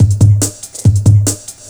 ELECTRO 05-R.wav